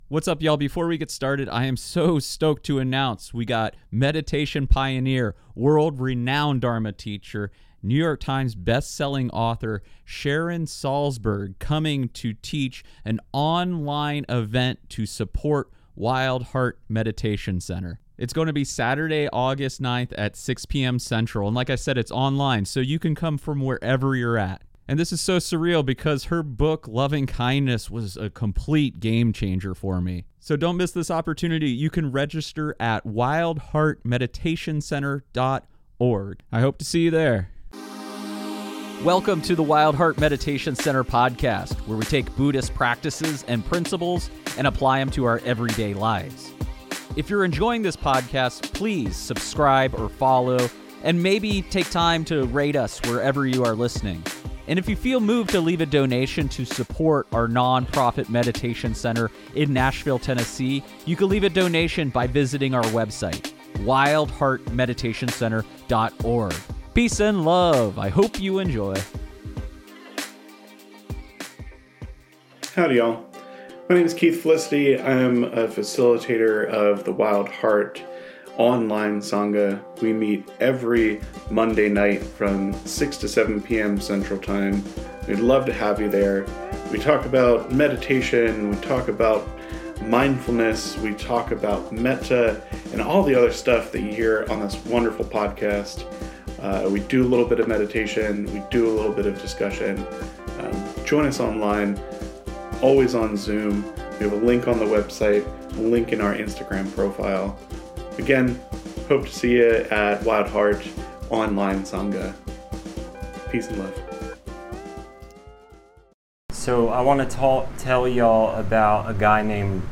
1 Loving Kindness Meditation Retreat - First Evening Dharma Talk - The Qualities of a Good Friend 47:16 Play Pause 6h ago 47:16 Play Pause Play later Play later Lists Like Liked 47:16 This episode was recorded at the Loving Kindness Meditation Retreat in Sewanee, TN July 2025.